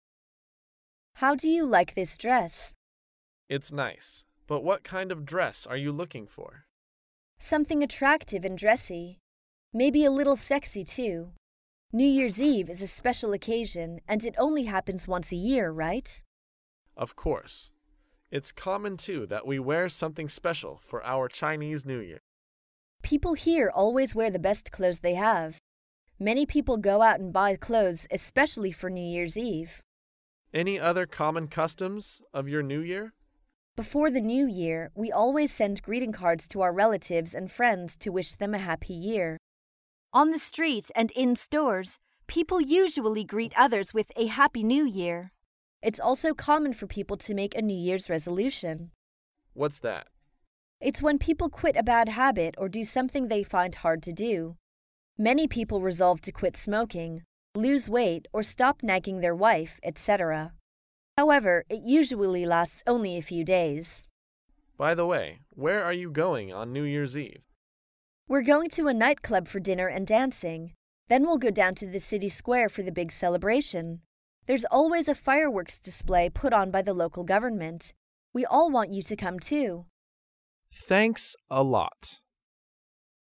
在线英语听力室高频英语口语对话 第336期:新年习俗的听力文件下载,《高频英语口语对话》栏目包含了日常生活中经常使用的英语情景对话，是学习英语口语，能够帮助英语爱好者在听英语对话的过程中，积累英语口语习语知识，提高英语听说水平，并通过栏目中的中英文字幕和音频MP3文件，提高英语语感。